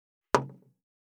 191,テーブル等に物を置く,トン,ゴト,ポン,ガシャン,ドスン,ストン,カチ,タン,バタン,スッ,サッ,コン,ペタ,パタ,チョン,コス,カラン,ドン,チャリン,効果音,環境音,BGM,
コップ効果音物を置く
コップ